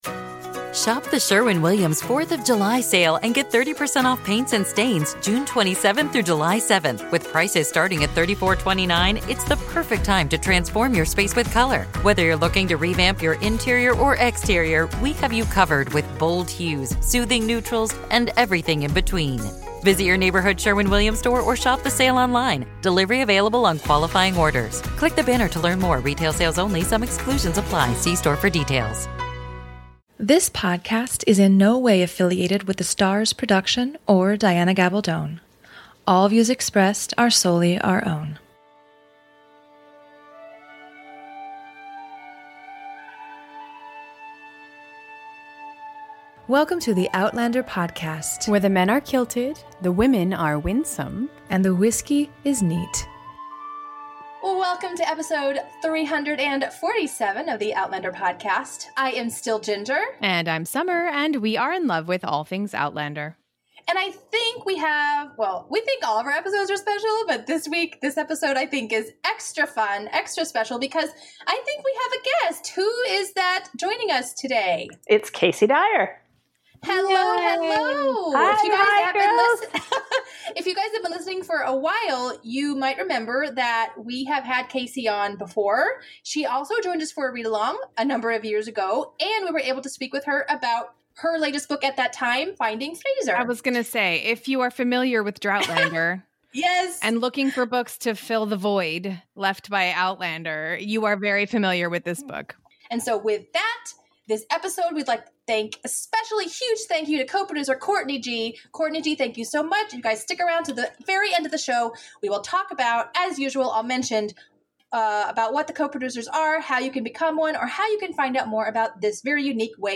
In this episode, we share our interview